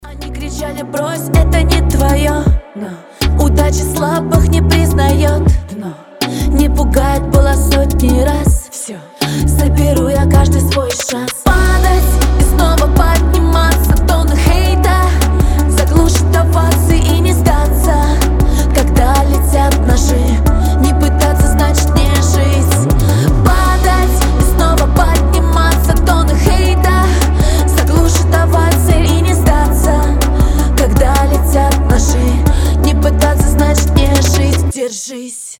• Качество: 320, Stereo
вдохновляющие
воодушевляющие